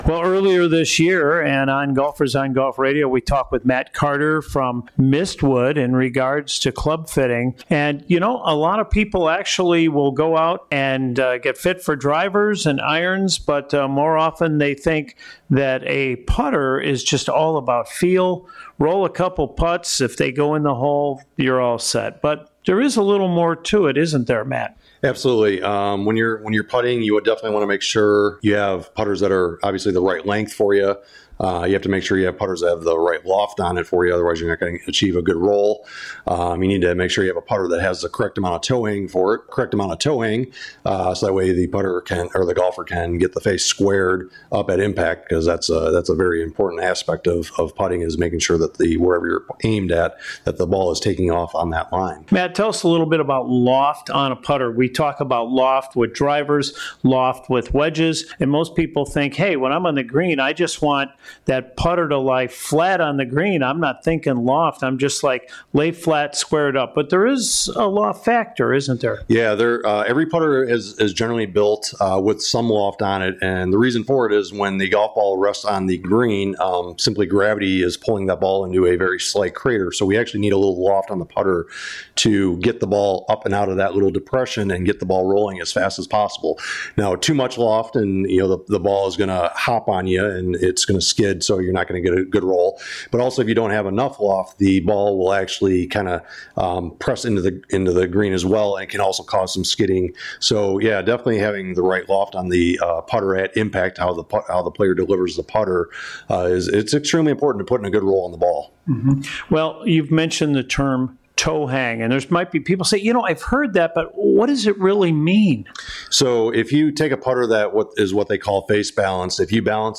Golfers on Golf Radio Chicago - Page 11 of 298 - LIVE broadcasts from 9am to 10am on Saturdays on WNDZ AM-750Golfers on Golf Radio Chicago | LIVE broadcasts from 9am to 10am on Saturdays on WNDZ AM-750 | Page 11